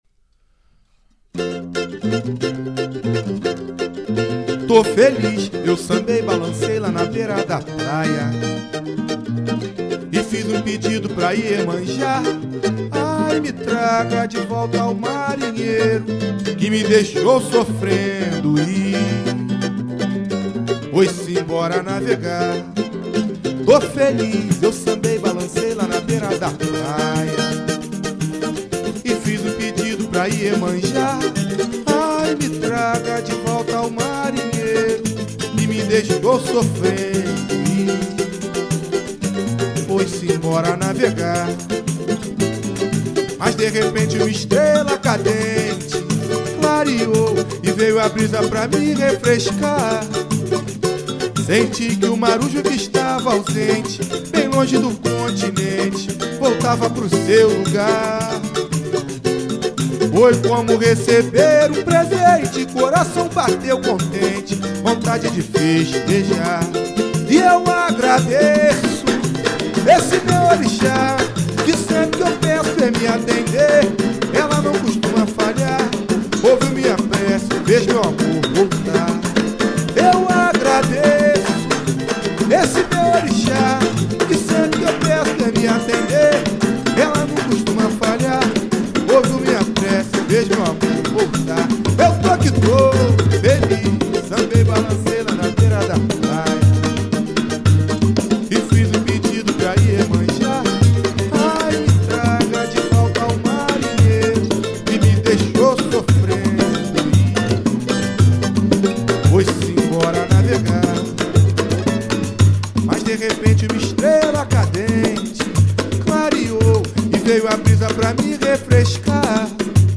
MPB Xote